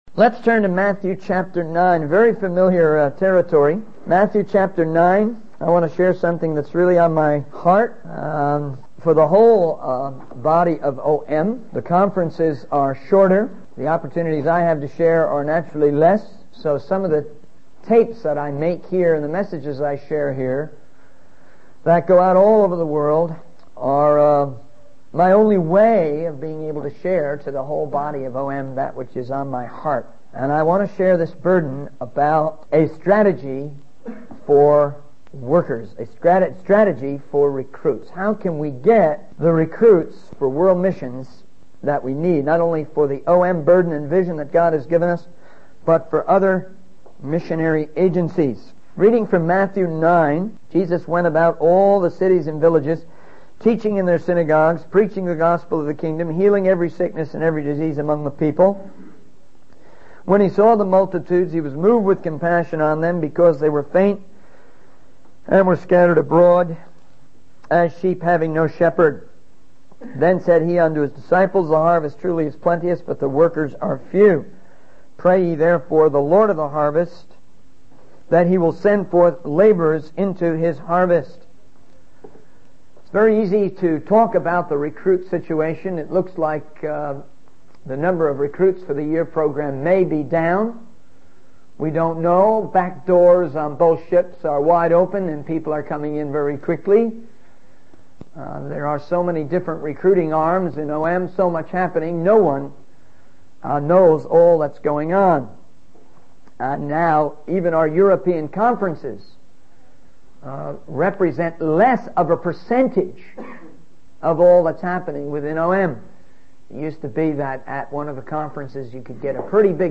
In this sermon, the speaker discusses the need for recruits in world missions and shares a strategy for attracting workers.